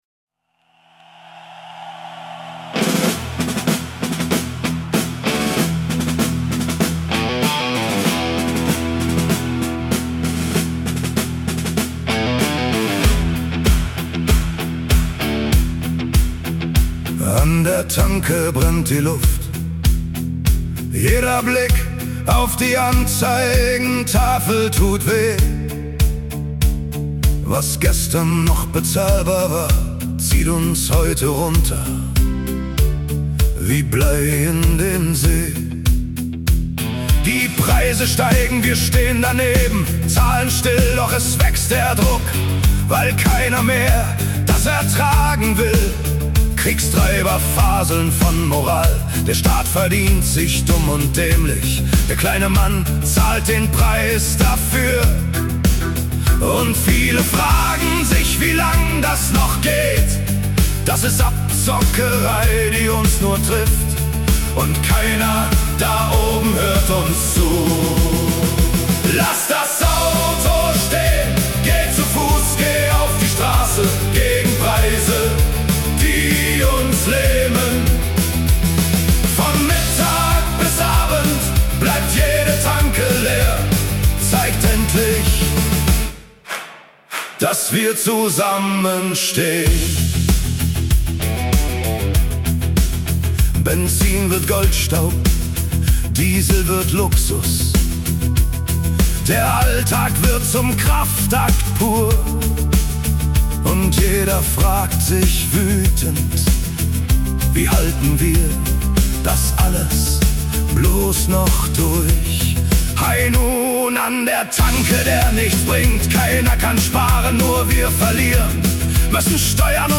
protest_song_high_noon_an_der_tanke_deutsch.mp3